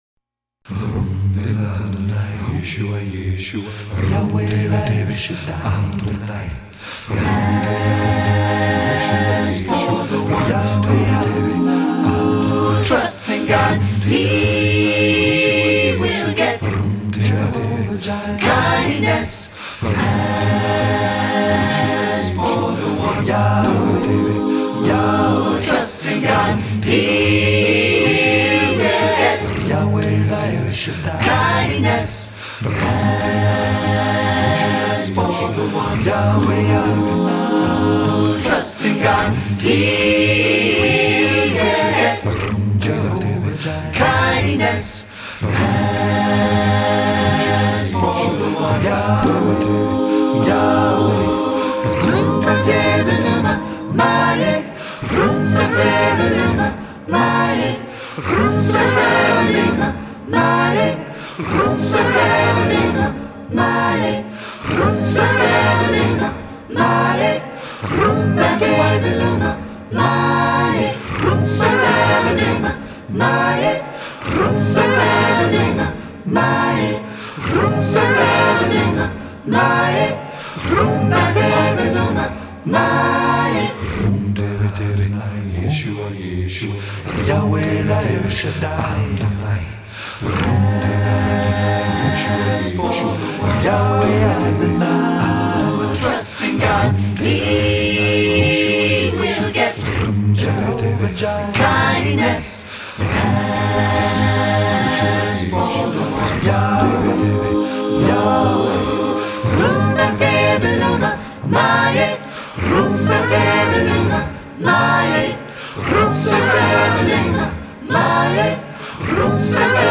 free christian music download
on lead guitar